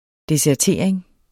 Udtale [ desæɐ̯ˈteɐ̯ˀeŋ ]